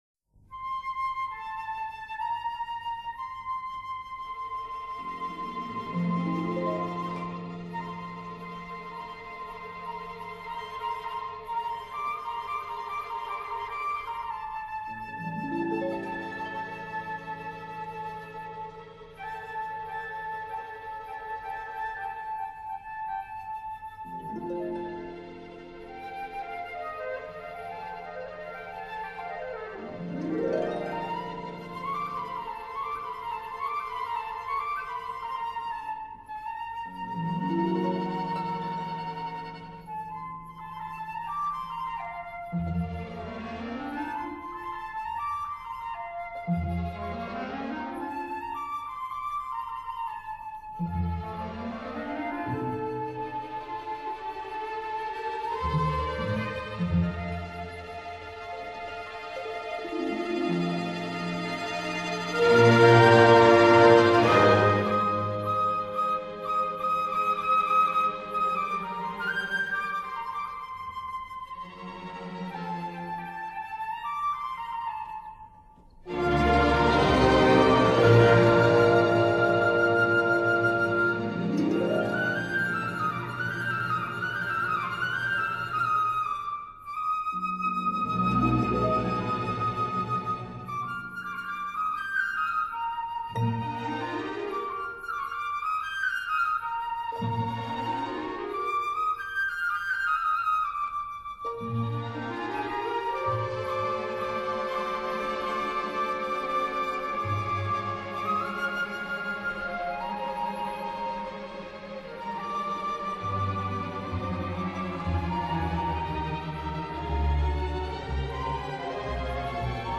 交响音画
小提琴柔和的振音，烘托出晨雾弥漫的碧绿群山，竖琴弹出了泉水的欢歌，朦胧的晨曦中飘荡出彝族清亮的笛声和深情的赞歌。